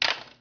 bow_drop.wav